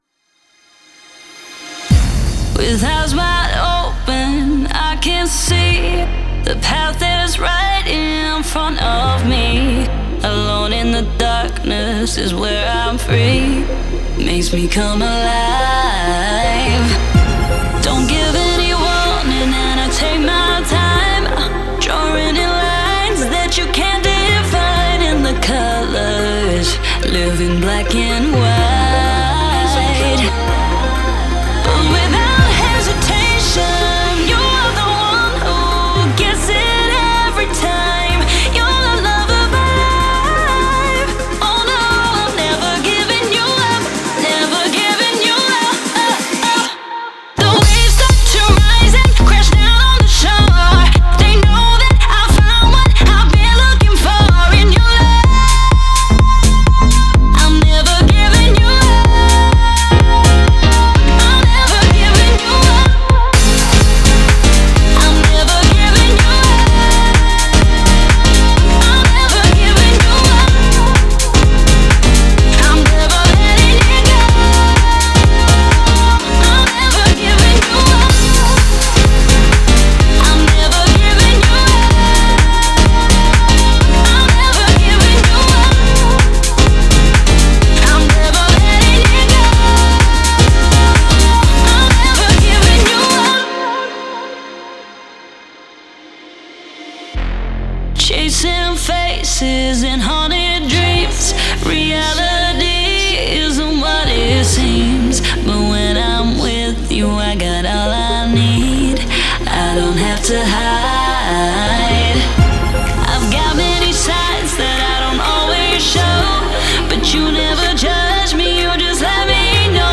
это энергичная поп-песня в жанре EDM